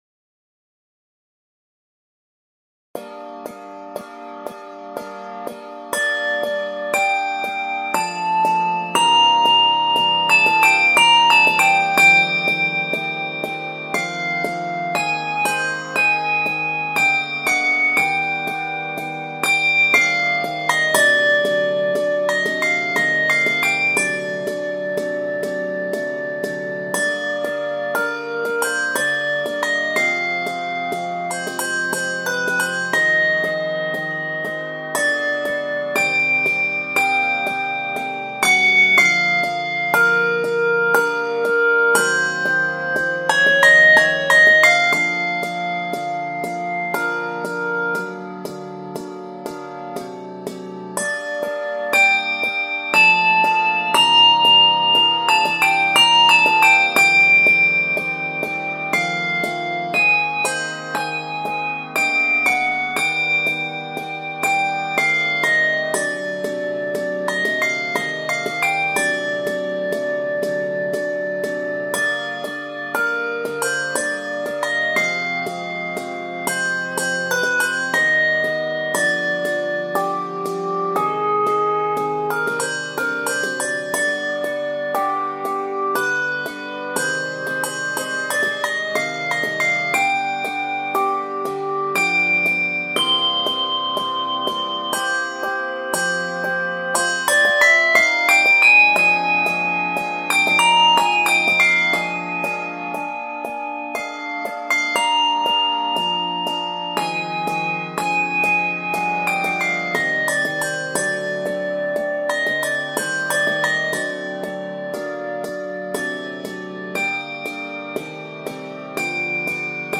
Key of g minor.